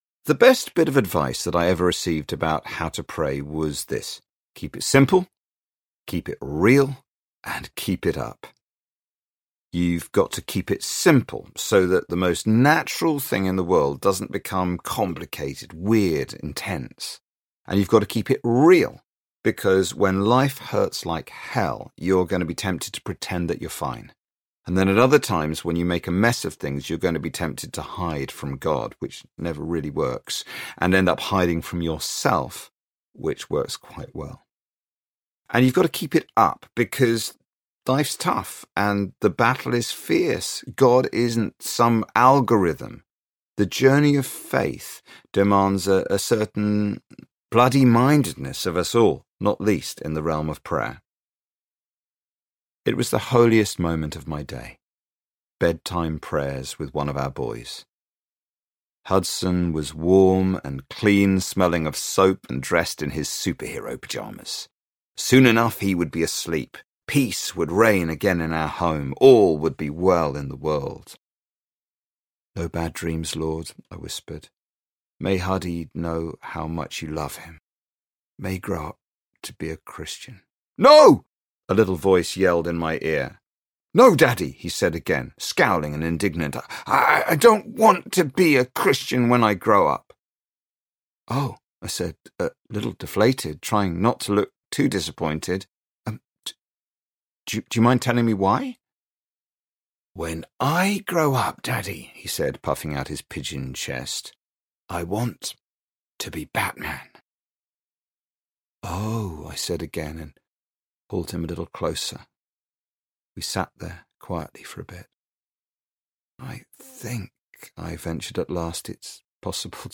How to Pray Audiobook